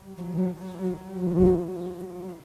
bees.3.ogg